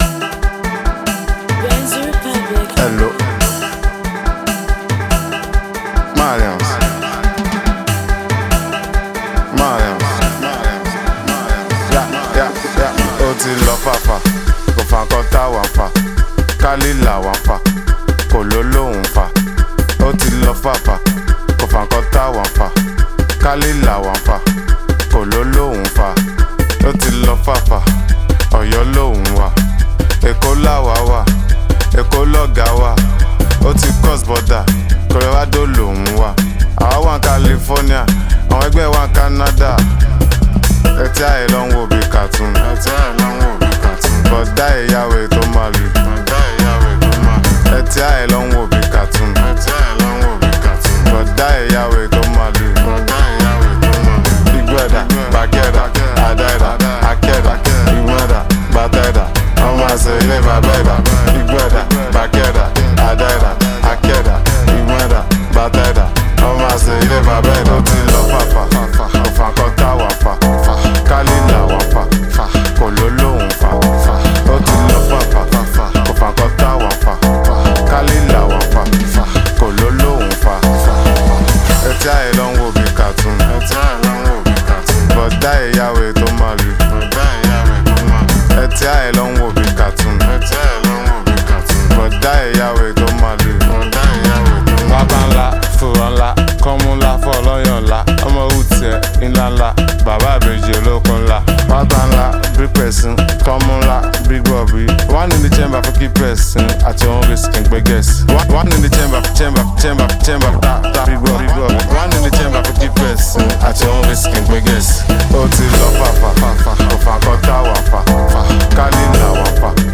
a perfect blend of catchy beats and lyrical flows